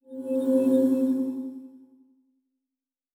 Teleport 10_2.wav